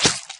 Knife Attack.ogg